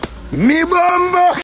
toggle-sound.mp3